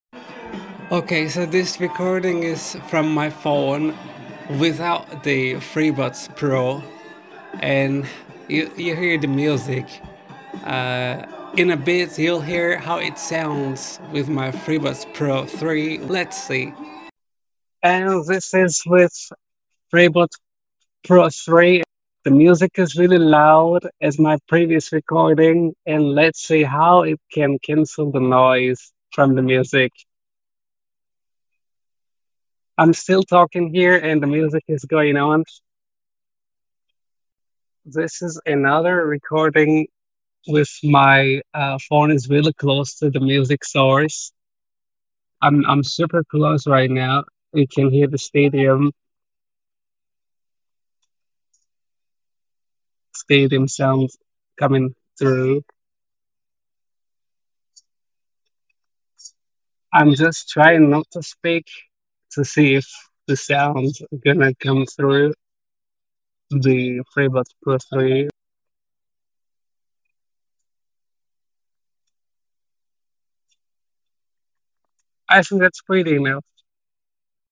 Microphone test
Huawei-Freebuds-pro-3-Microphone-Samples.mp3